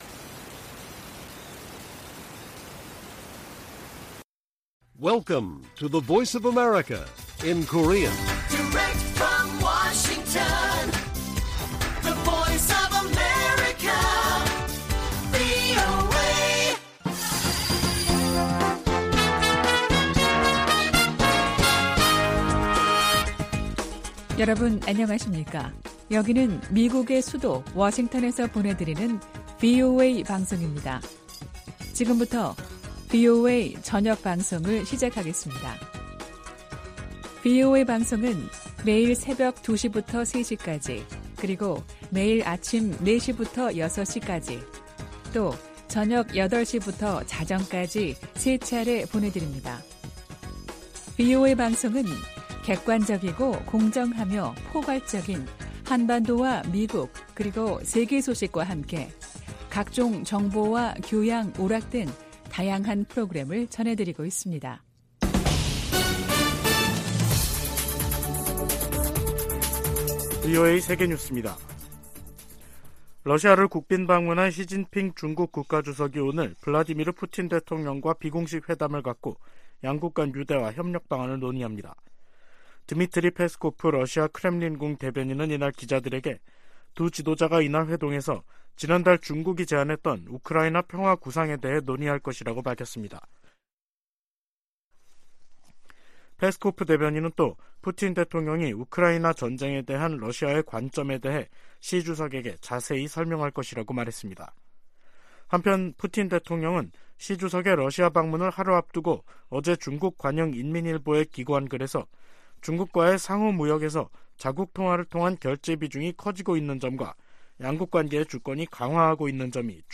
VOA 한국어 간판 뉴스 프로그램 '뉴스 투데이', 2023년 3월 20일 1부 방송입니다. 북한은 김정은 국무위원장이 참관한 가운데 한국의 주요 대상을 겨냥한 핵 반격 전술훈련을 했다며 또 다시 핵 공격 위협을 가했습니다. 북한의 탄도미사일 발사 등 도발적 행동이 역내 불안정을 초래하고 있다고 미국 인도태평양사령관이 말했습니다. 유엔 안보리 회의에서 미국이 북한 인권과 대량상살무기 문제가 직결돼 있다며 이를 함께 제기해야 한다고 강조했습니다.